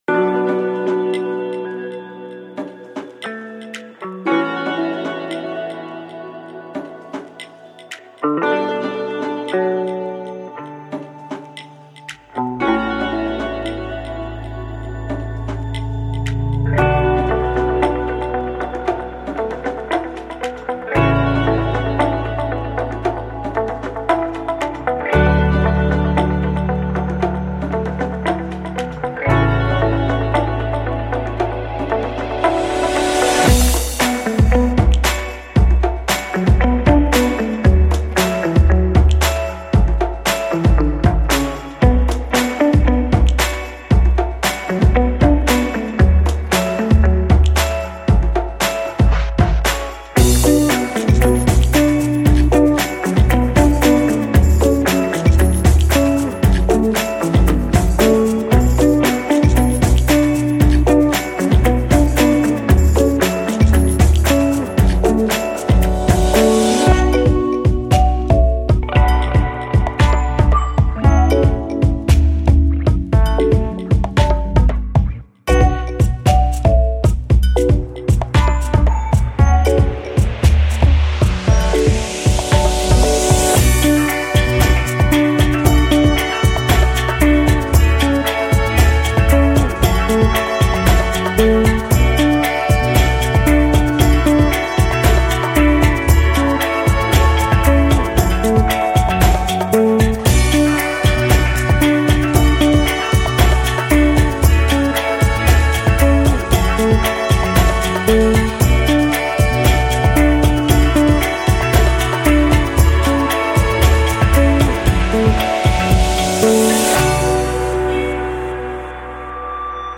Фоновая музыка для видео без слов, легкая и роялти фри